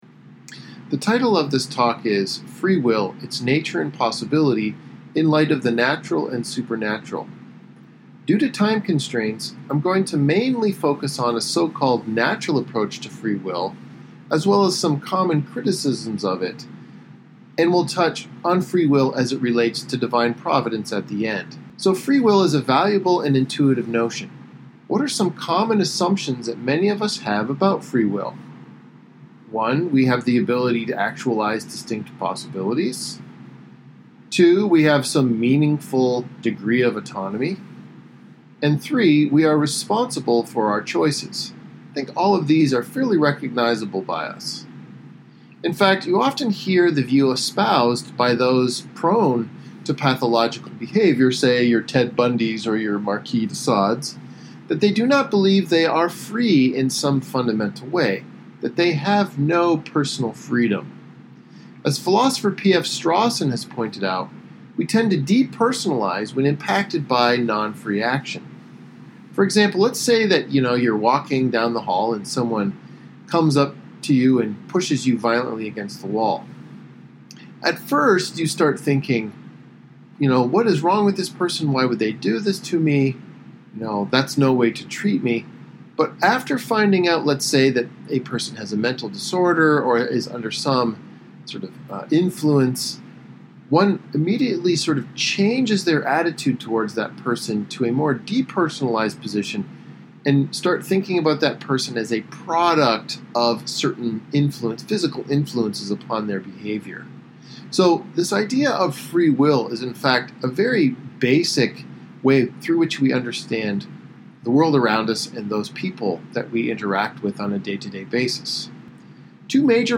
This lecture was given at Ohio State University on 16 April 2019.